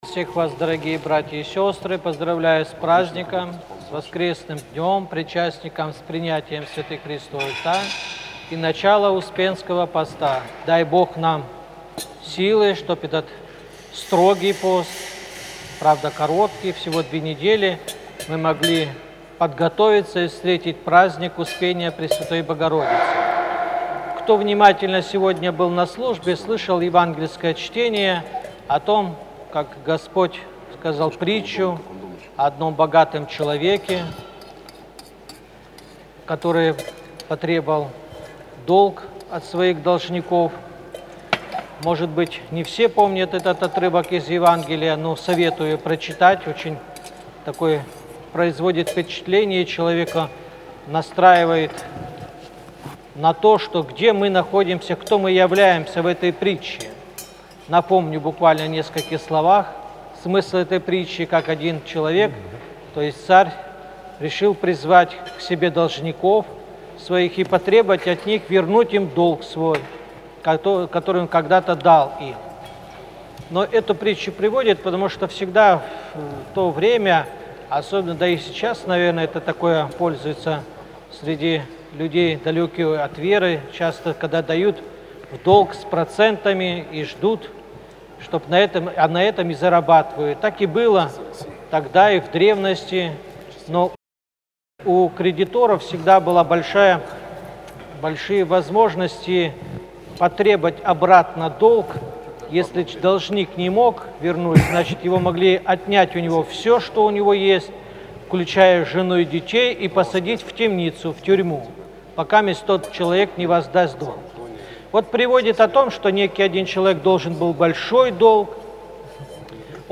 Неделя 11-я по Пятидесятнице - Кафедральный собор Христа Спасителя г. Калининграда